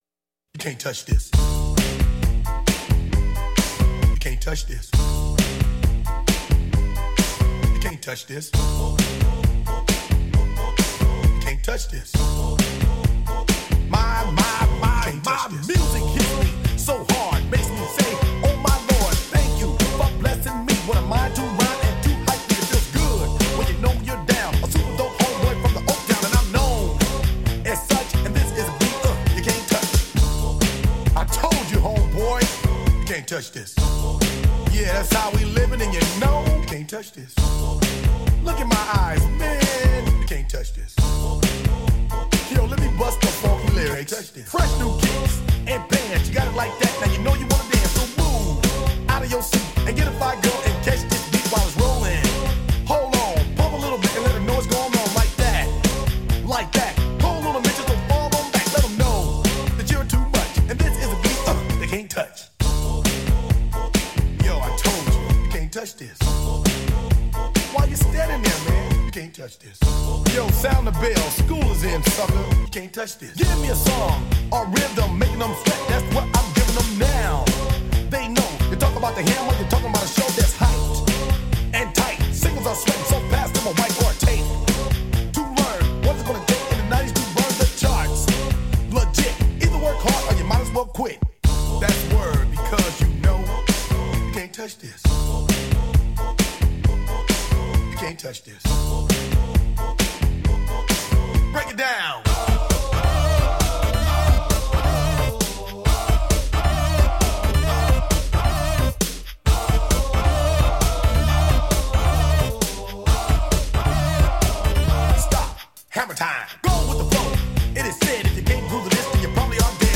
HipHop 90er